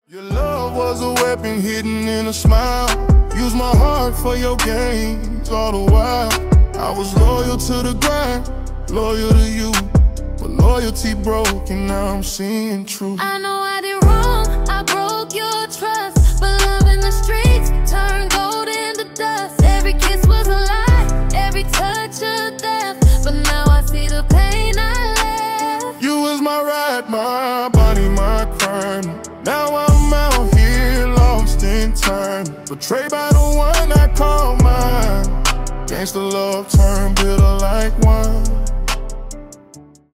дуэт
rnb